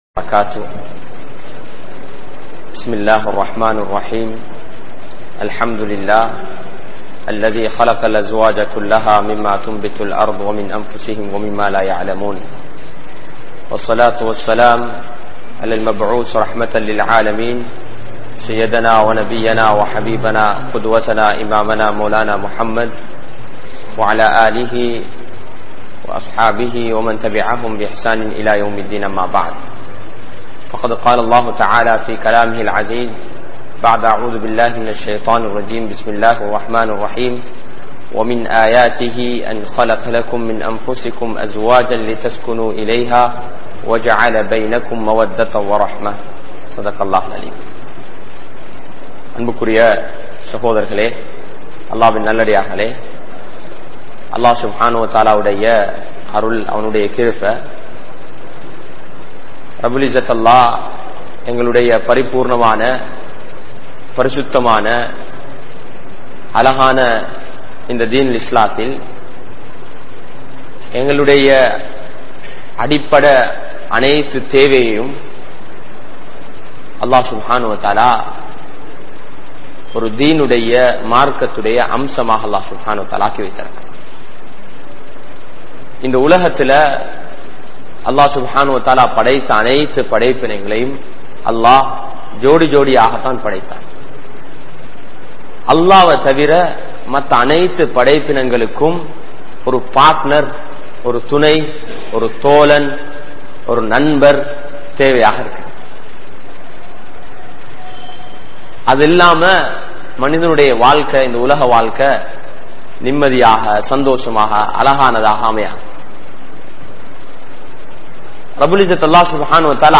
Manaivien Meethu Anpu Kaatugal | Audio Bayans | All Ceylon Muslim Youth Community | Addalaichenai